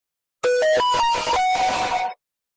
Loud samsung notification sound effect sound effects free download